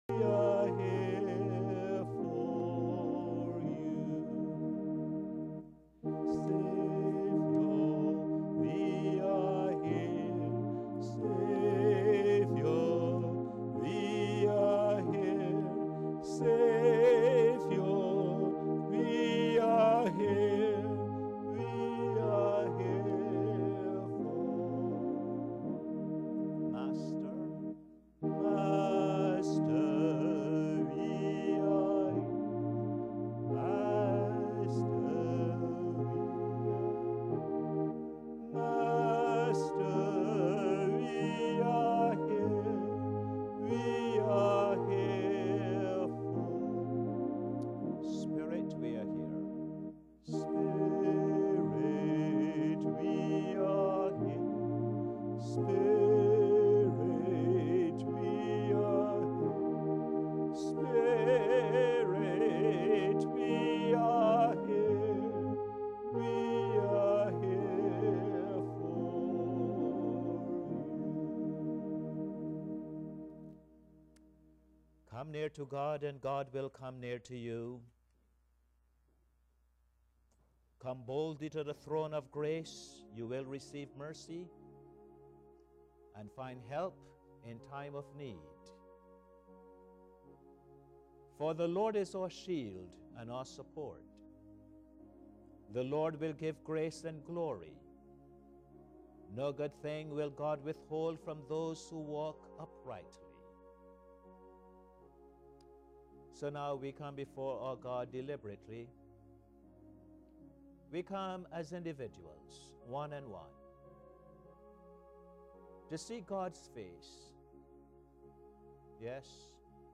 Posted in Sermons on 13.